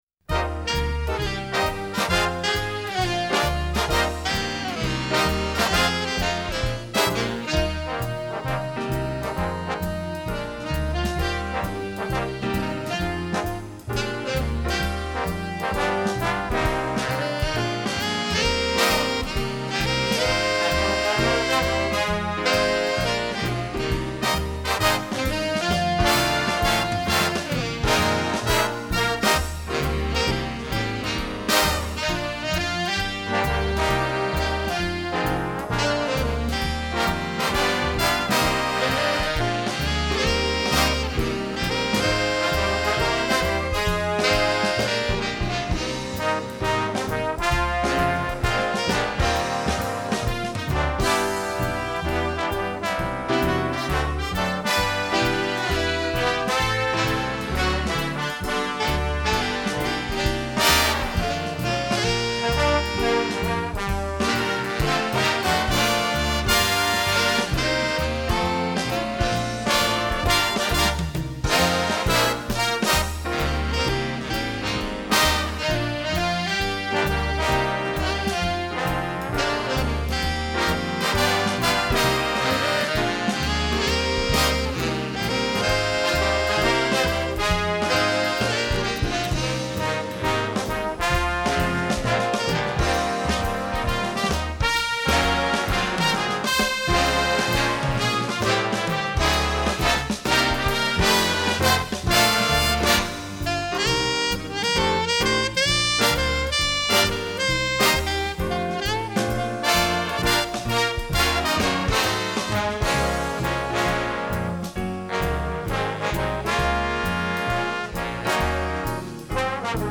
Besetzung: Jazz-Ensemble